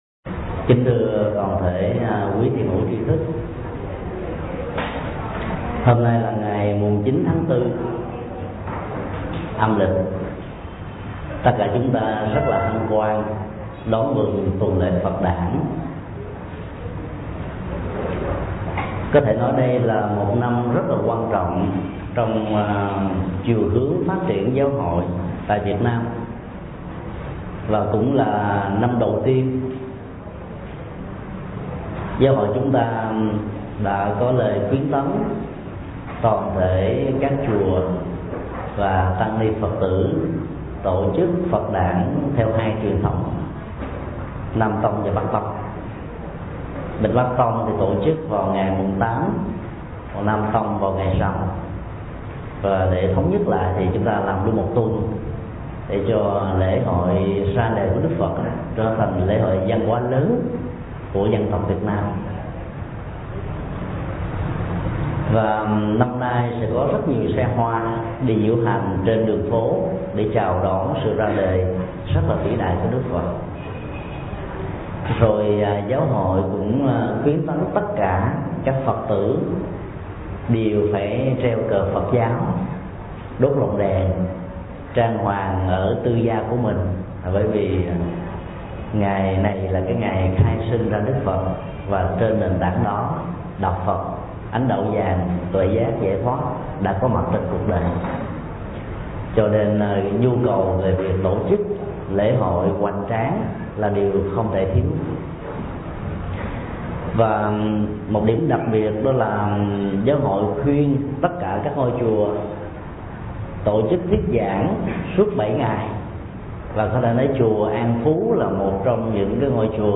Mp3 Thuyết Giảng Giải phóng sân hận – Thầy Thích Nhật Từ Giảng tại Chùa An Phú, Sài Gòn, mùng 9 tháng 4 ÂL, ngày 16 tháng 5 năm 2005